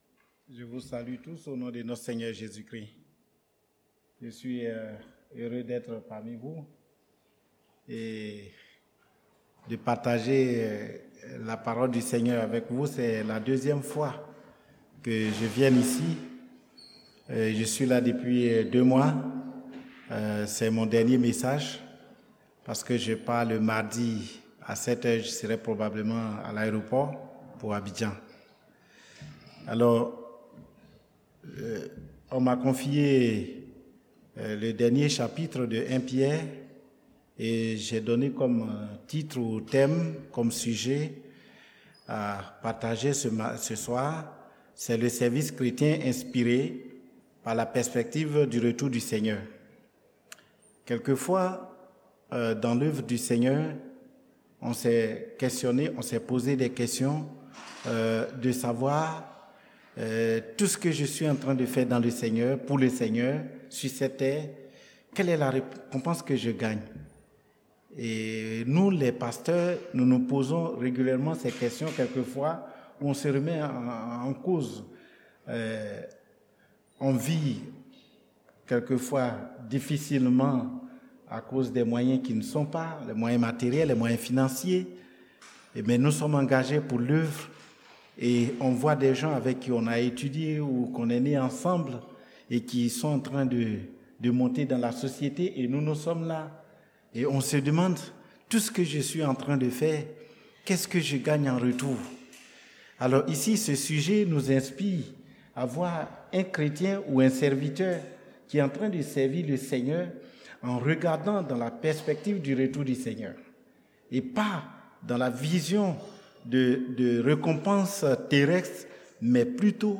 Le service chrétien inspiré par la perspective du retour du Seigneur – 1 Pierre 5 : 1-14 – Église Chrétienne Missionnaire de Saint-Maur